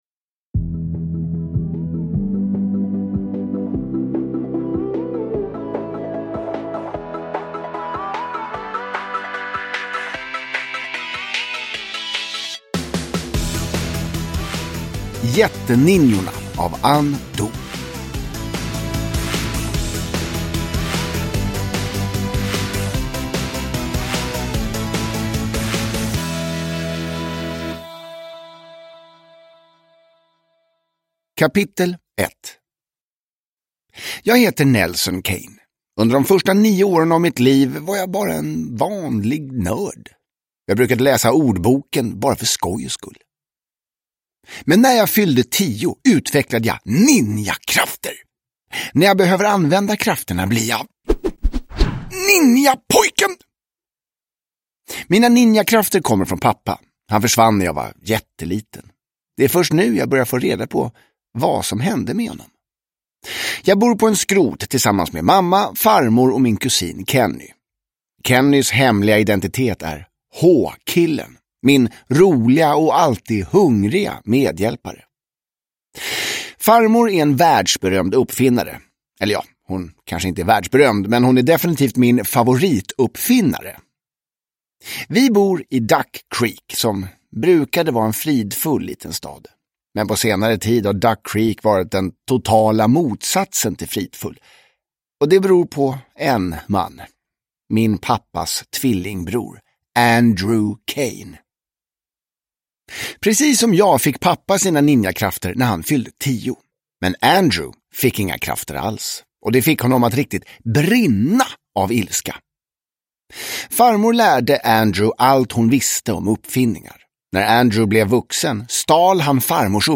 Jätteninjorna – Ljudbok – Laddas ner